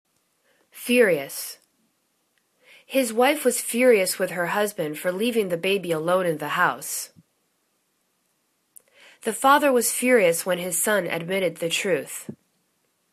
fu.ri.ous     /'fyu:rioəs/    n